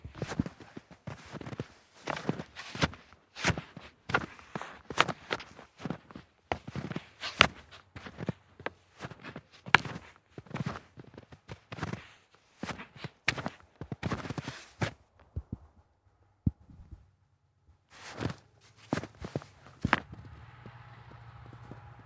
winter » footsteps shoes packed snow medium slight speed uphill effort mic close
描述：footsteps shoes packed snow medium slight speed uphill effort mic close.flac
标签： snow packed shoes footsteps
声道立体声